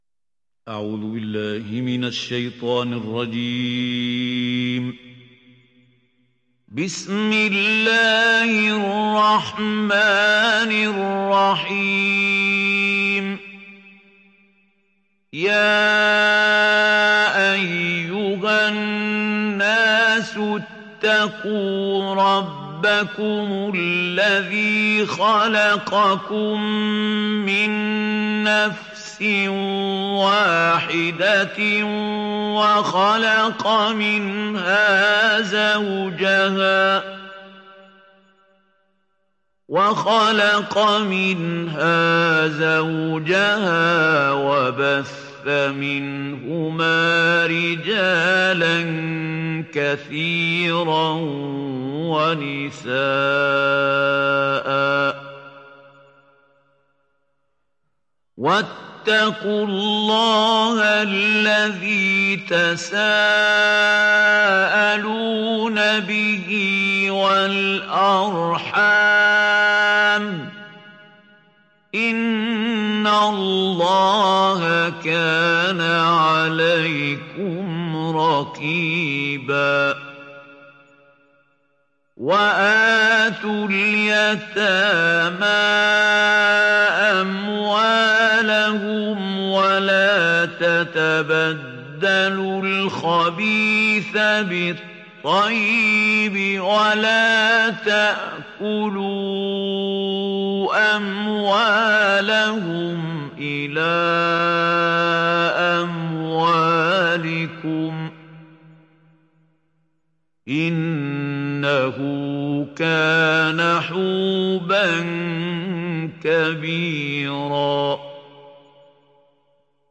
Sourate Annisa Télécharger mp3 Mahmoud Khalil Al Hussary Riwayat Hafs an Assim, Téléchargez le Coran et écoutez les liens directs complets mp3